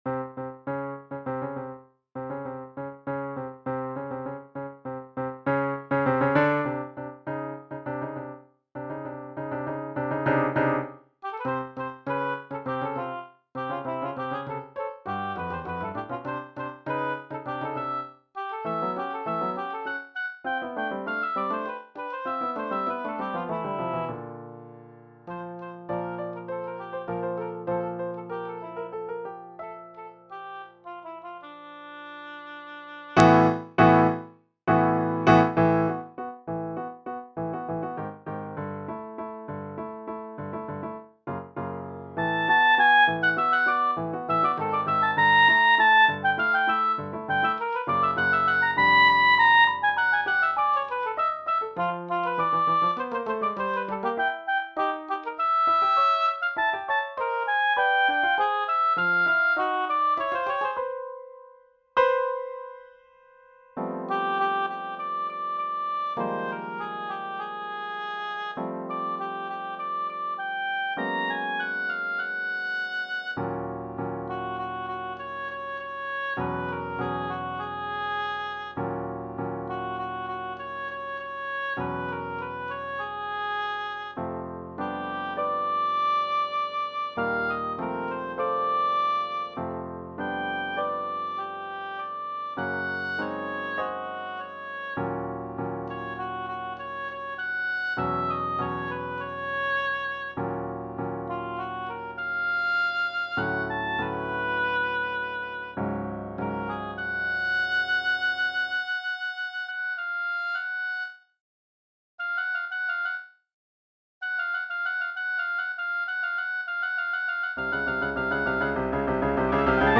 Oboe
Oboe Sonatina (1976) Piano score pdf Oboe Part pdf Midi file